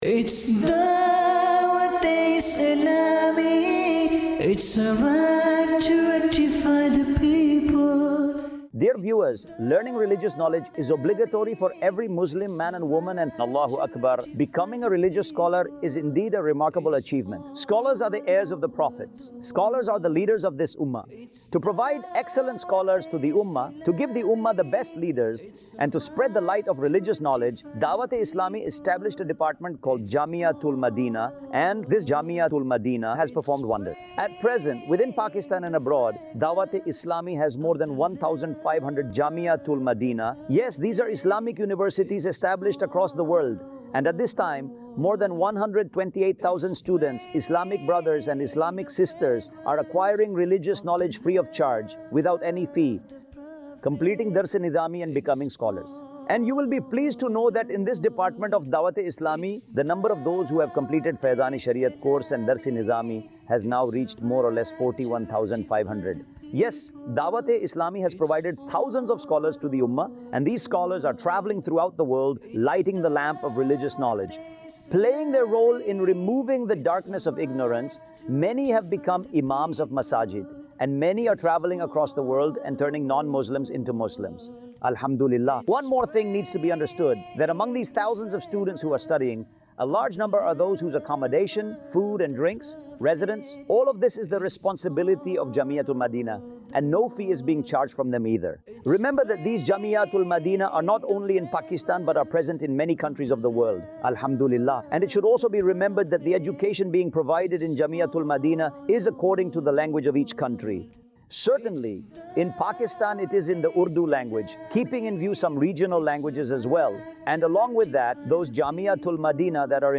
khutba
Jamia-Tul-Madinah | Department of Dawateislami | Documentary 2026 | AI Generated Audio